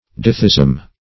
Ditheism \Di"the*ism\, n. [Pref. di- + theism: cf. F.
ditheism.mp3